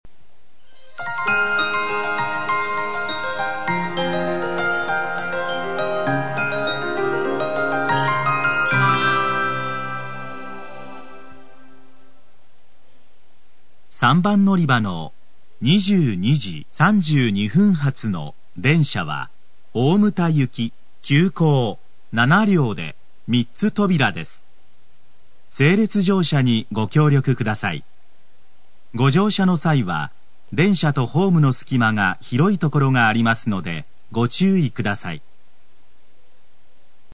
＜スピーカー＞　天井埋込型
＜曲名（本サイト概要）＞　西鉄新主要　／　全線-男性放送
○メロディー+案内放送（急行・大牟田） 2014年平日のG223列車です。